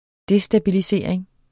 Udtale [ ˈdesdabiliˌseɐ̯ˀeŋ ]